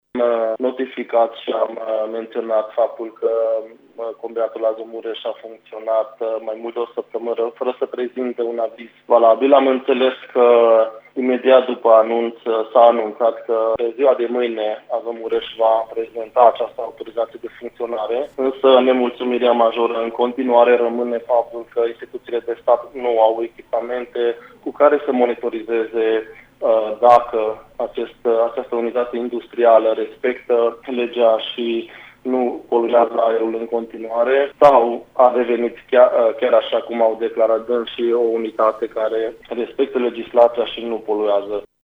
Vicepreşedintele UDMR Mureş, Soos Zoltan, a solicitat, ieri, instituţiilor abilitate ‘să restabilească normalitatea şi să închidă imediat combinatul Azomureş’, pe motiv că ar funcționa ilegal.